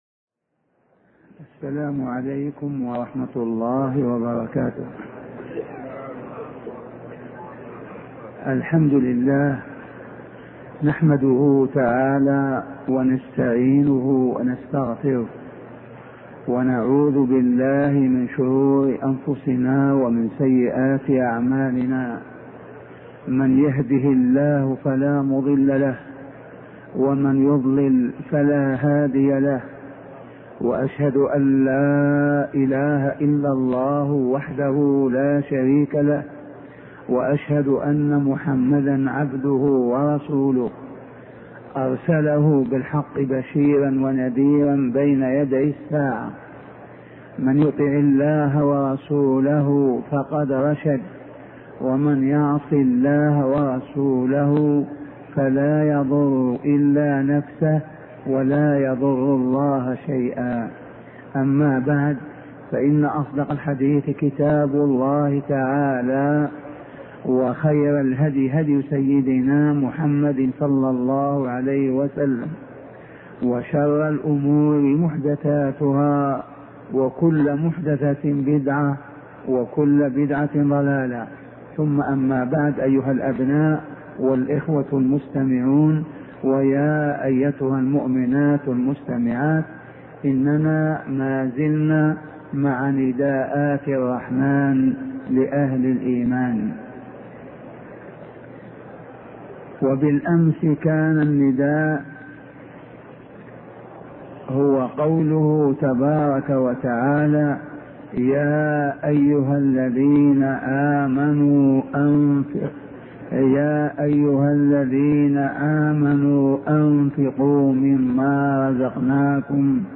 شبكة المعرفة الإسلامية | الدروس | نداءات الرحمن لأهل الإيمان 008 |أبوبكر الجزائري